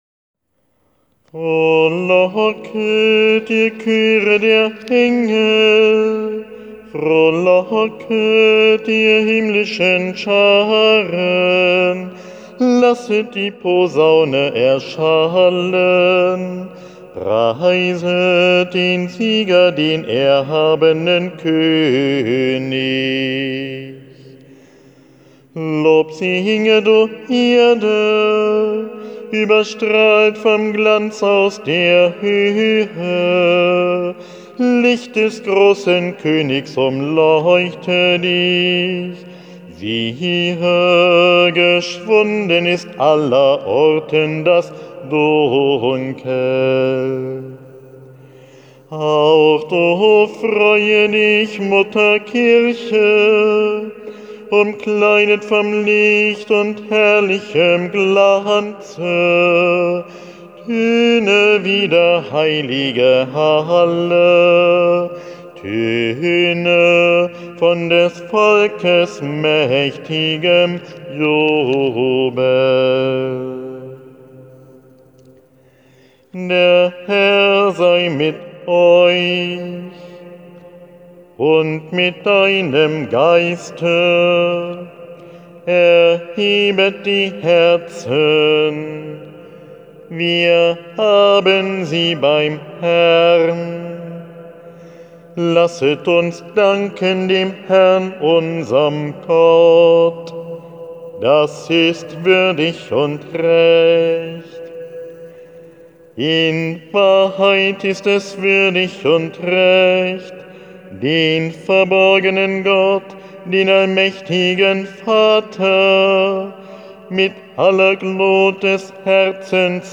Das Osterlob - Ruf als Audiodatei: